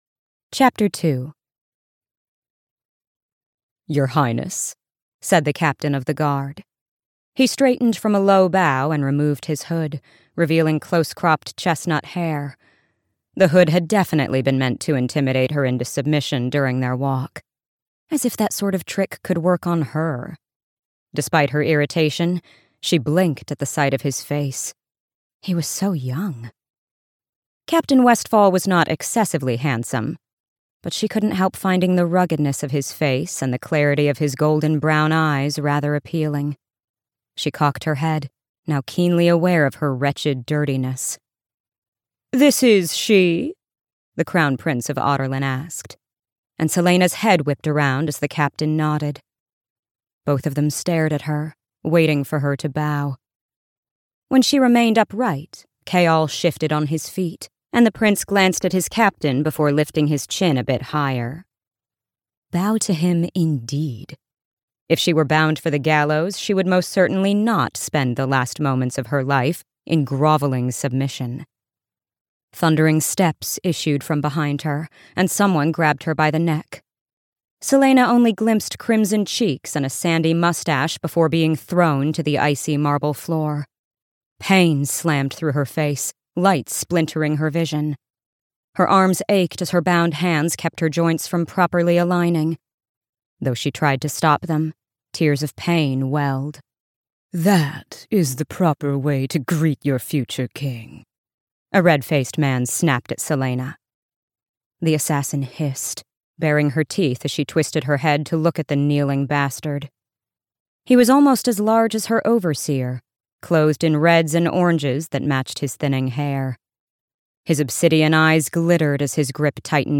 Throne of Glass (EN) audiokniha
Ukázka z knihy